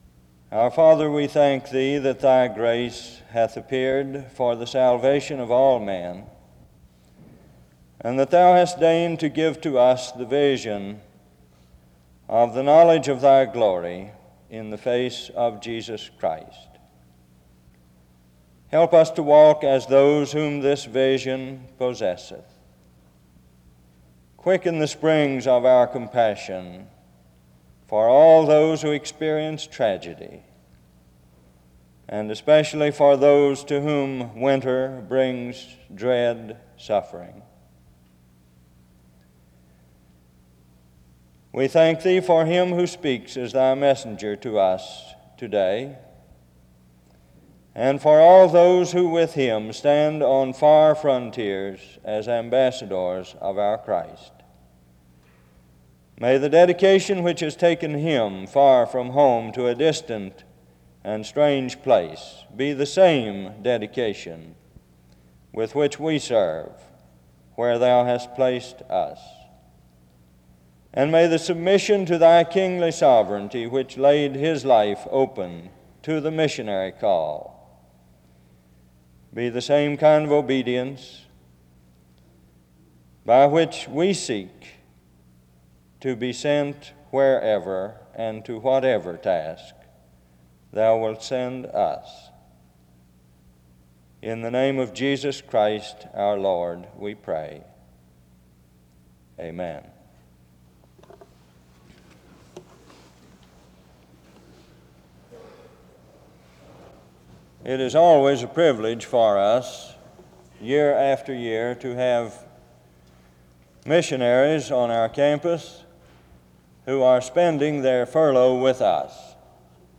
SEBTS Chapel
In Collection: SEBTS Chapel and Special Event Recordings SEBTS Chapel and Special Event Recordings